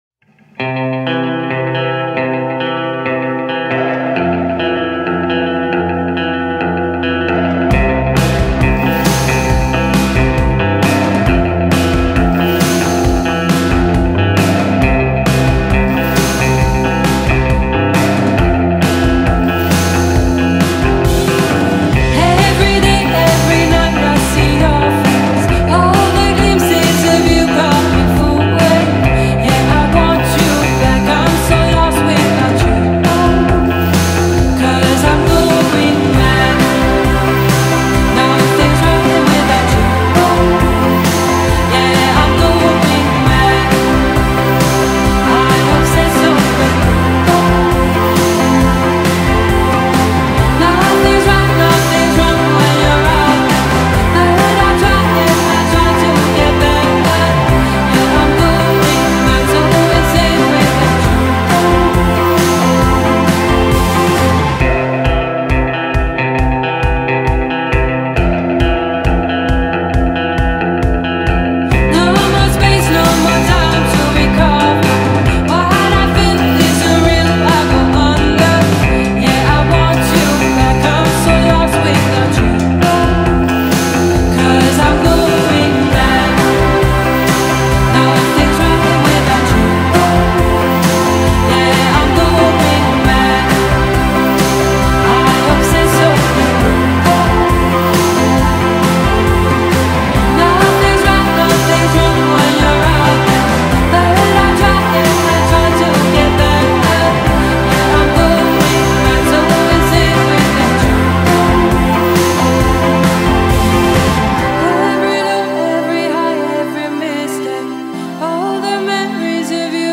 December 11, 2016 / / dream pop